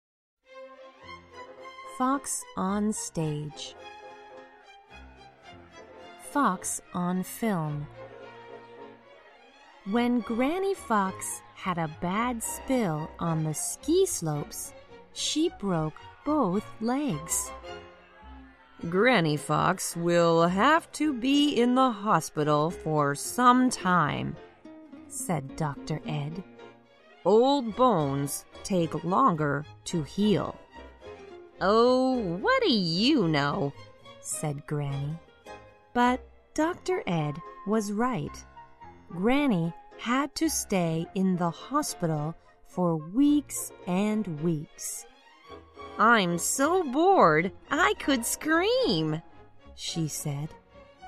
在线英语听力室小狐外传 第63期:小狐演戏的听力文件下载,《小狐外传》是双语有声读物下面的子栏目，非常适合英语学习爱好者进行细心品读。故事内容讲述了一个小男生在学校、家庭里的各种角色转换以及生活中的趣事。